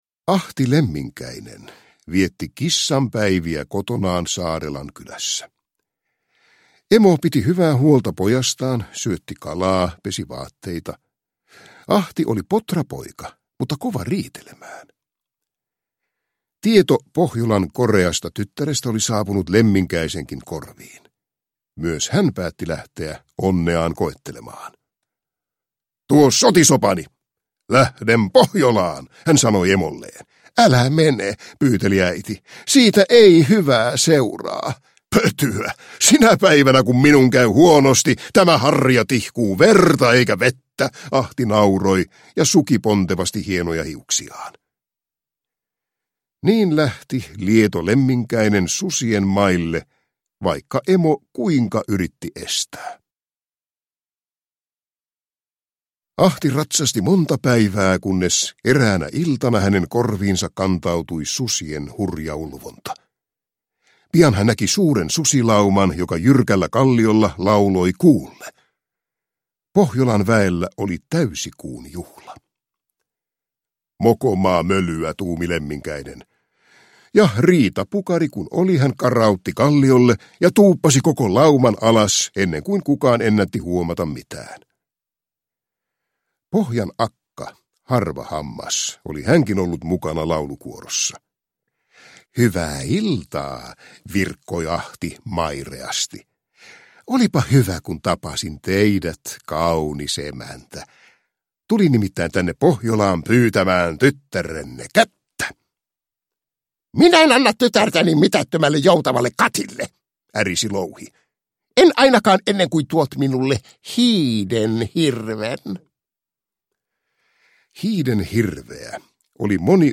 Koirien Kalevala – Ljudbok – Laddas ner